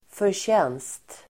Uttal: [förtj'en:st]